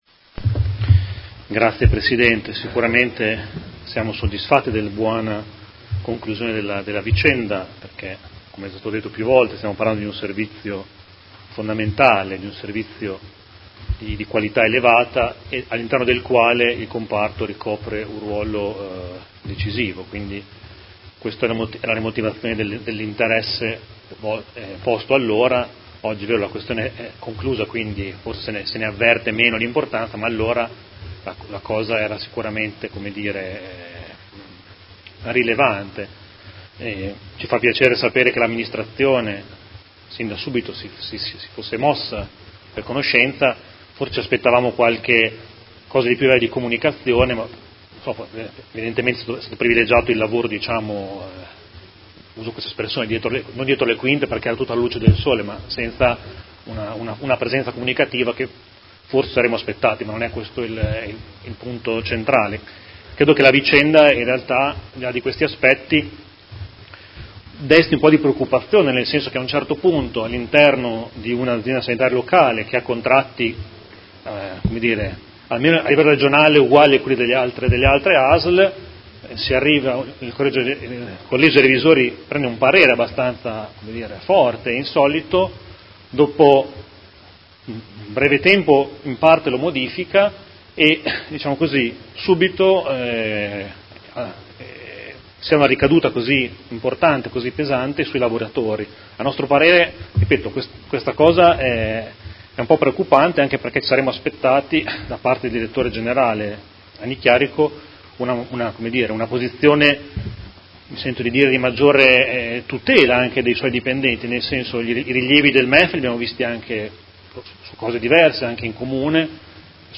Seduta del 10/01/2019 Replica a risposta Assessora Urbelli. Interrogazione del Gruppo Consiliare Movimento cinque Stelle avente per oggetto: Sospensione degli incentivi da parte dei Revisori dei Conti per il Comparto dell’AUSL di Modena